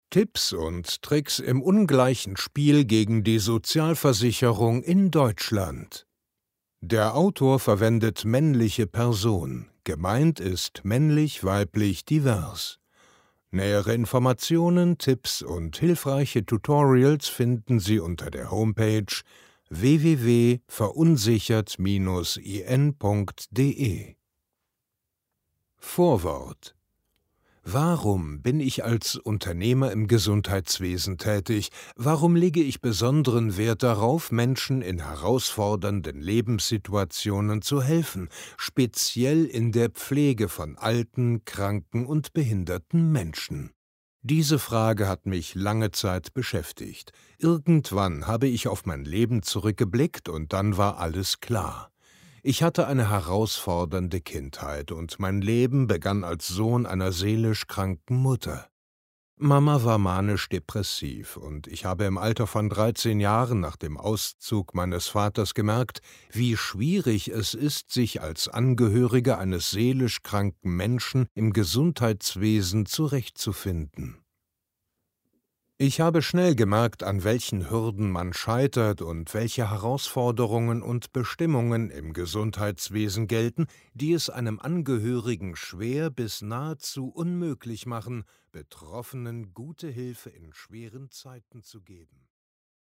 Hörbuchproduktion - Studio - Aufnahme - Synchronsprecher